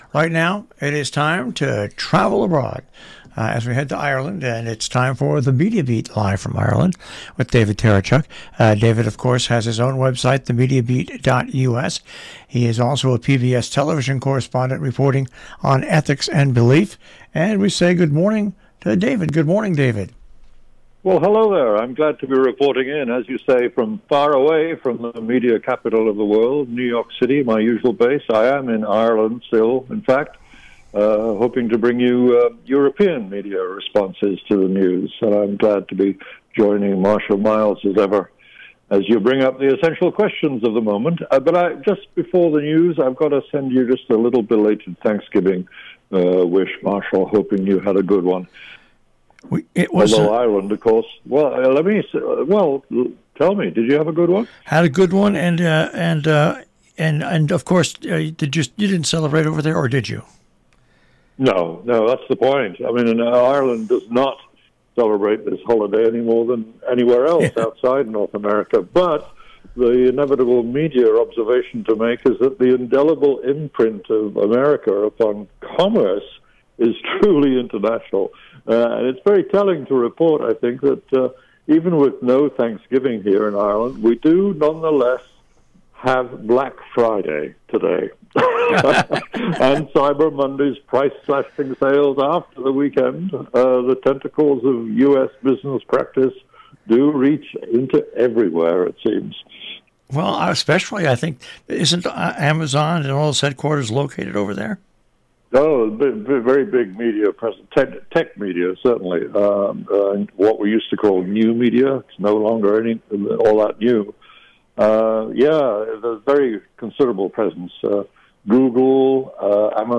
LIVE FROM IRELAND!